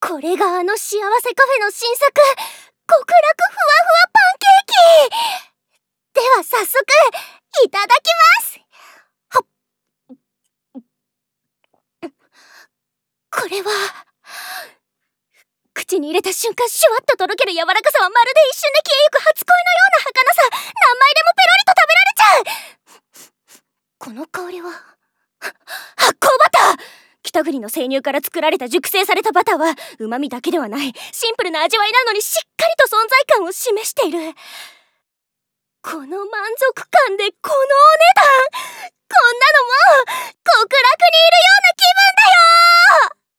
ボイスサンプル1.mp3